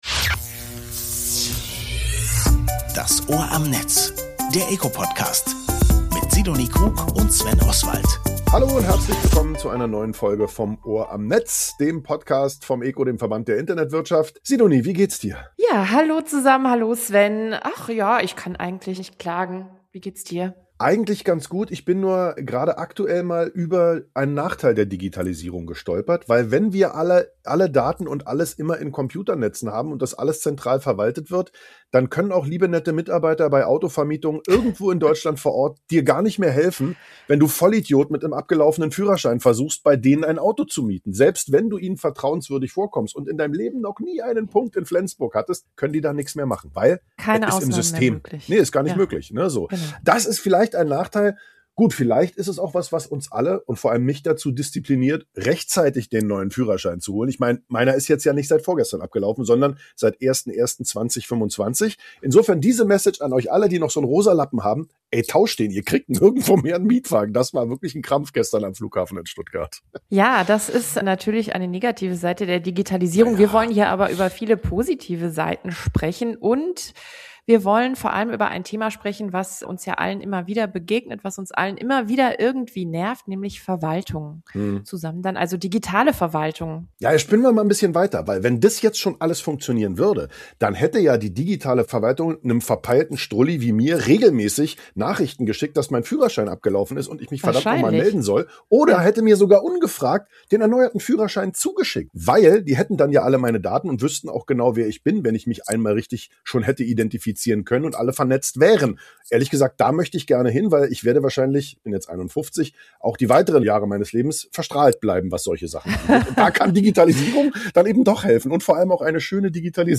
Martina Klement ist Staatssekretärin für Digitalisierung und Chief Digital Officer des Landes Berlin.